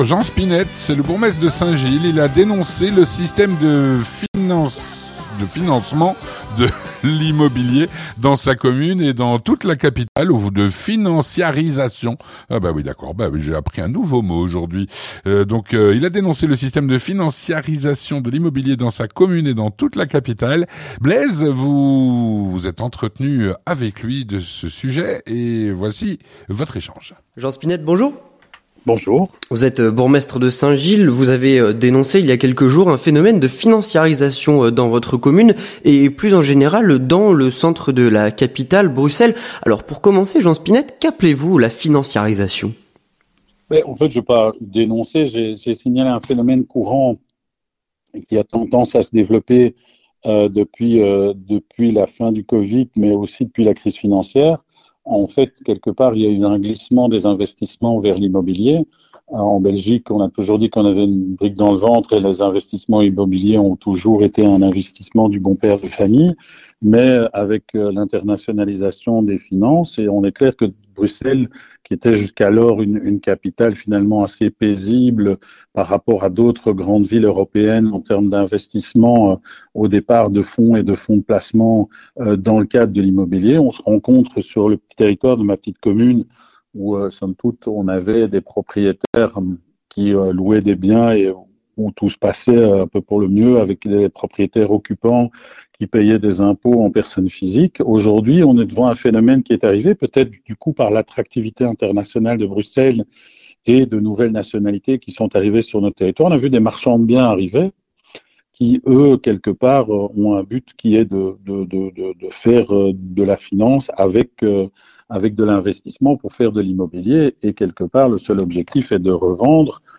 Avec Jean Spinette, Bourgmestre de Saint-Gilles